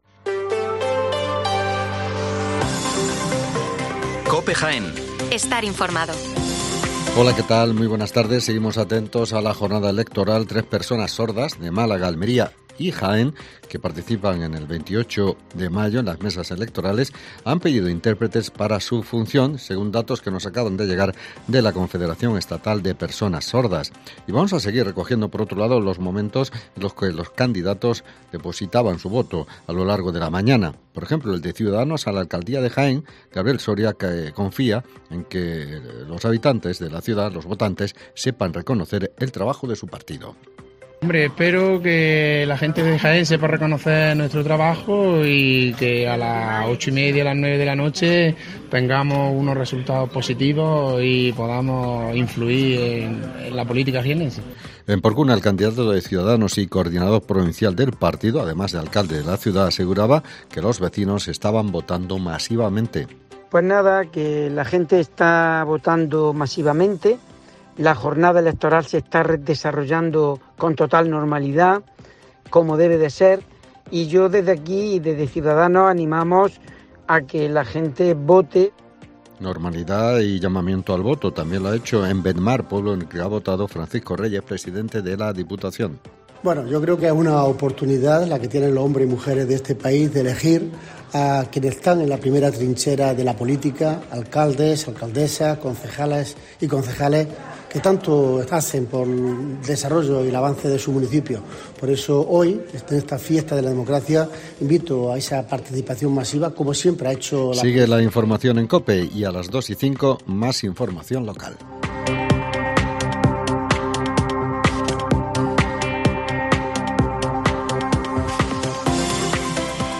Especial Elecciones Municipales en Jaén. El informativo de las 13:05 horas